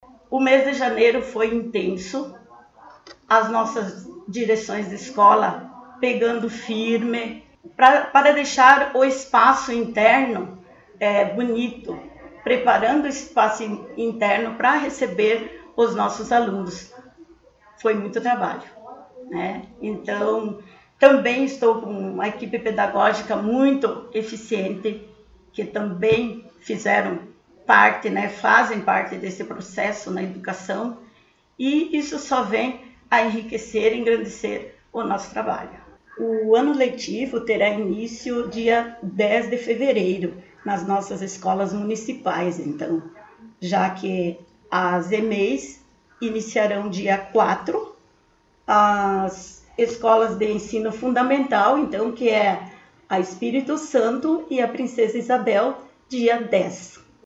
Secretária Municipal de Educação, Cultura e Desporto concedeu entrevista